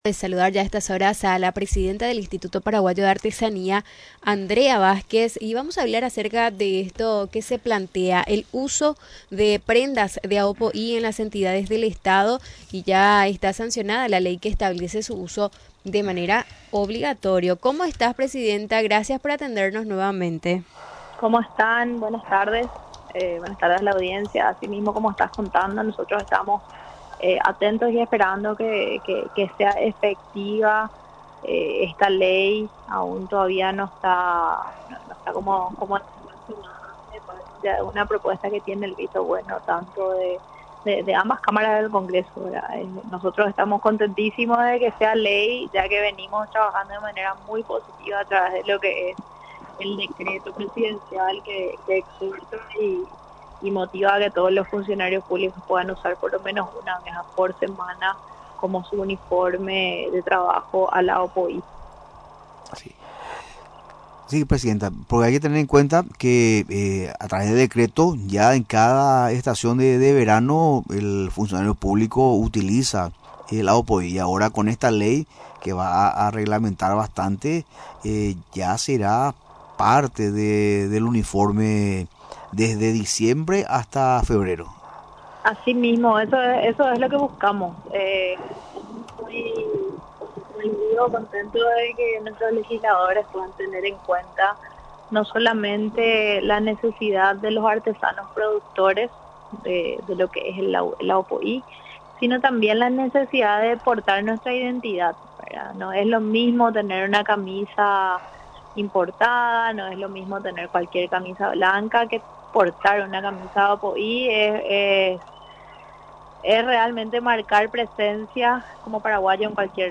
Durante la entrevista en Radio Nacional del Paraguay, destacó que este proyecto traerá beneficio a los artesanos y a toda la cadena de producción.